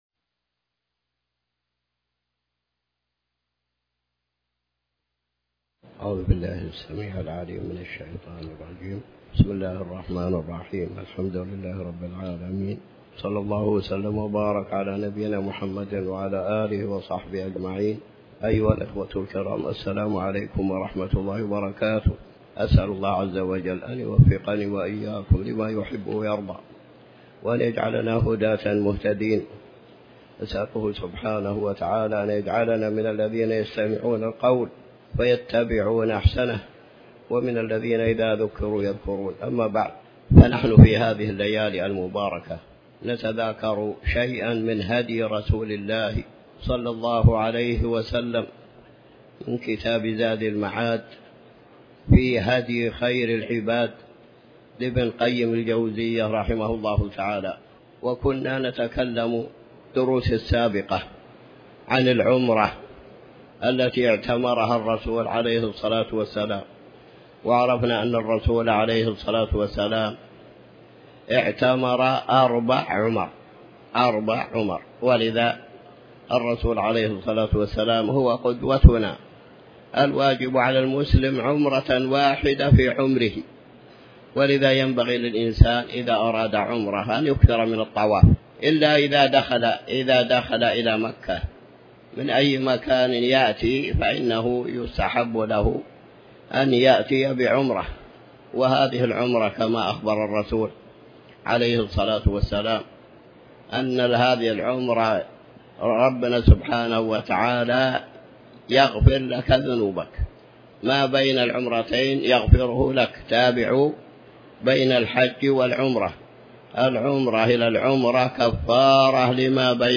تاريخ النشر ٢٣ محرم ١٤٤٠ هـ المكان: المسجد الحرام الشيخ